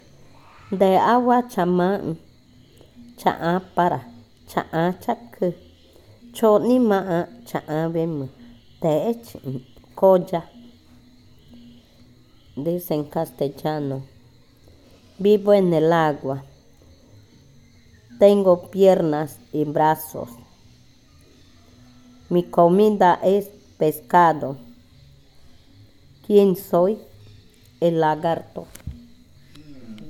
Adivinanza 27. Lagarto negro
Cushillococha